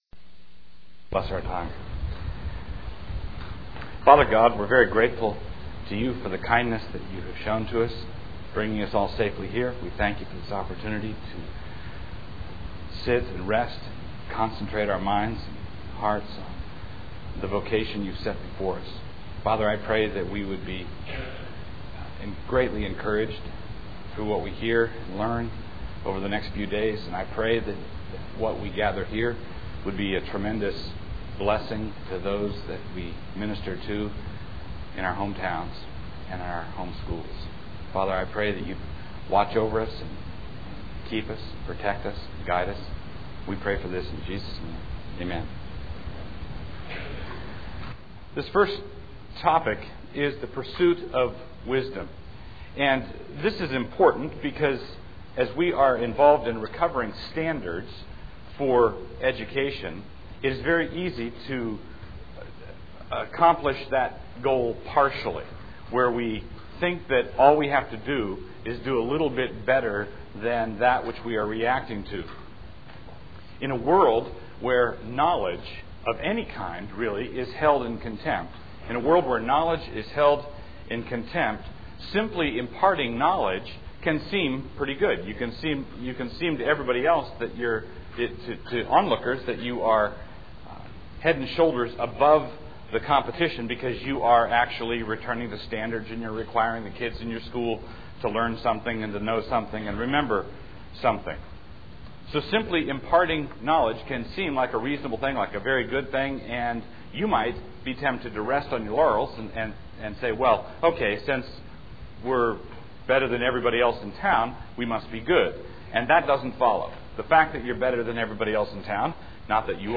2000 Workshop Talk | 1:00:51 | All Grade Levels, Virtue, Character, Discipline
He is the author of numerous books on classical Christian education, the family, and the Reformed faith Additional Materials The Association of Classical & Christian Schools presents Repairing the Ruins, the ACCS annual conference, copyright ACCS.